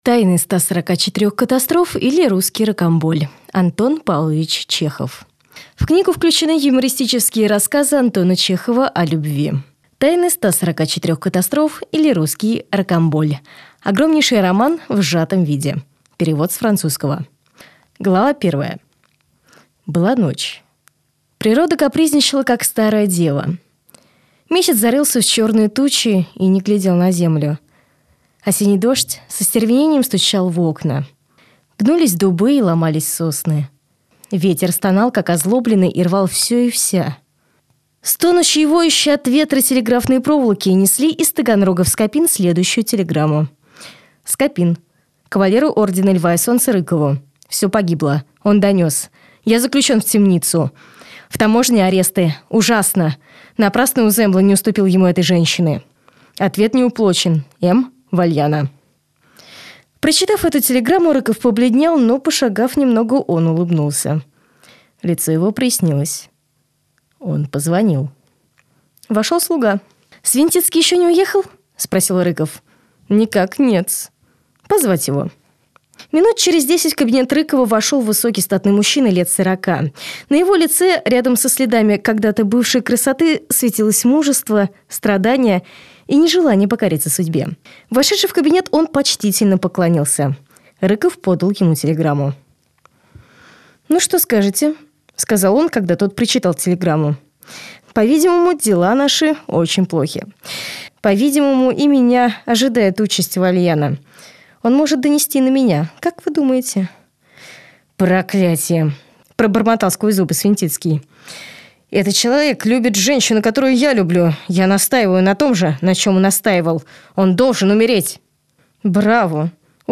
Аудиокнига Тайны 144 катастроф, или Русский Рокамболь | Библиотека аудиокниг